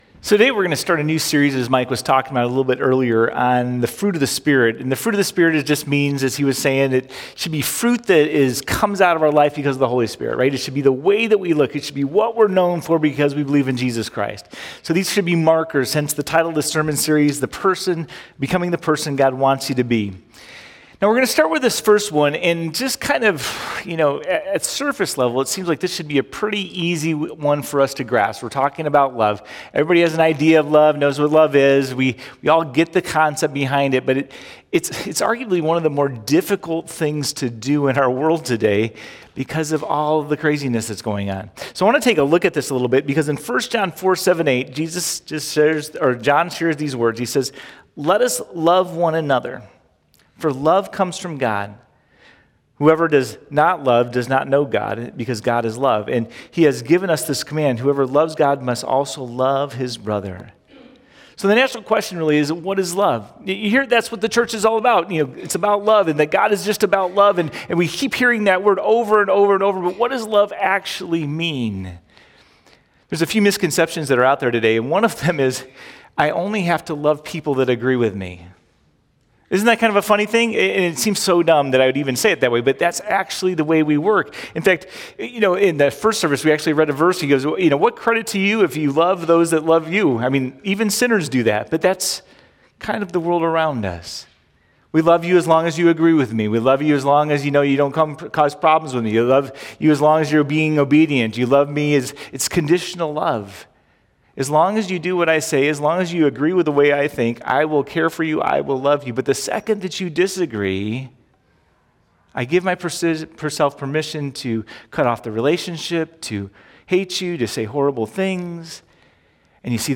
0505-Sermon.mp3